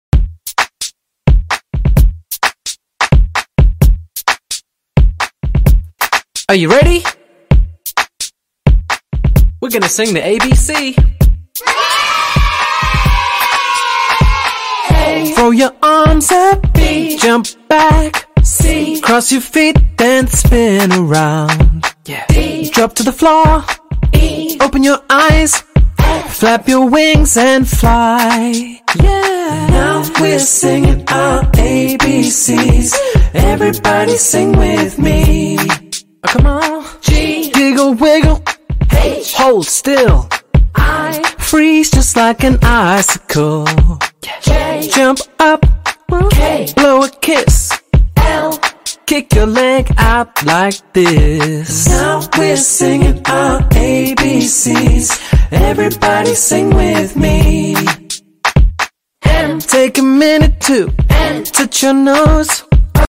Music for Kids ｜ Kindergarten Songs for Children